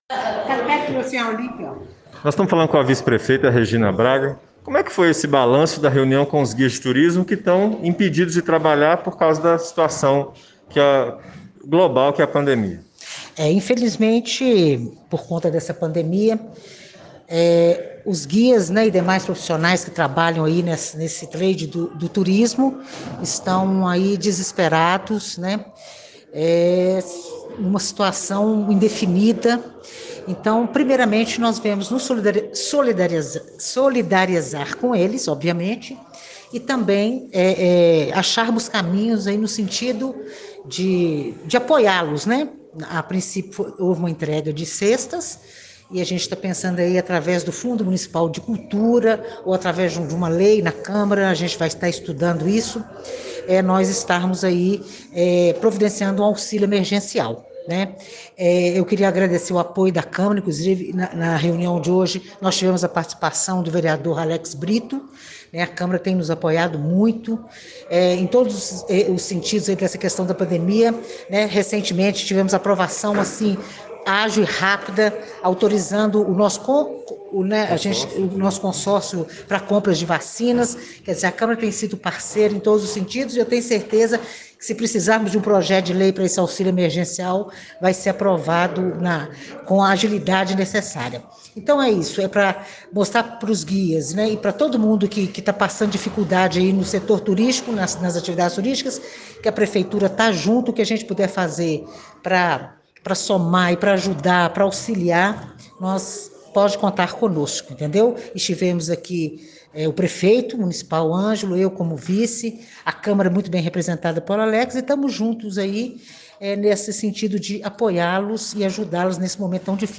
Angelo Oswaldo fala sobre a reunião com os guias.